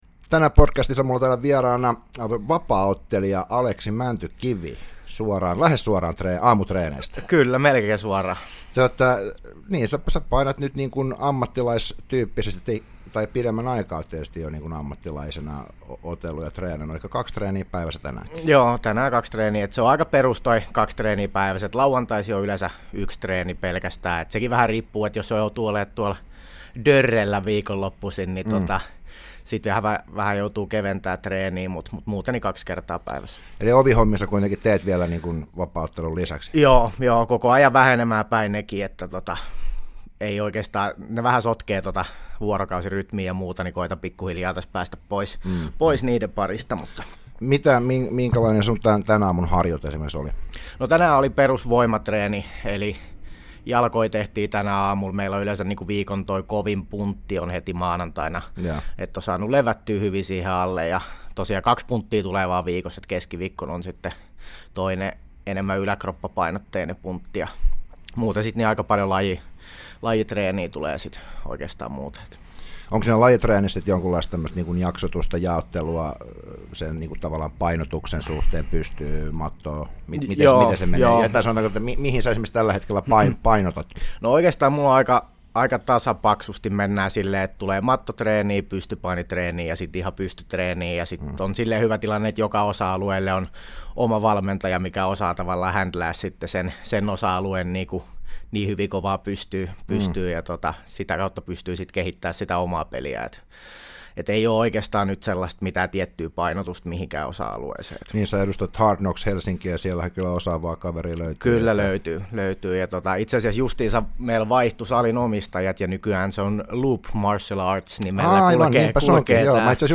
Vieraana ammattilaisvapaaottelija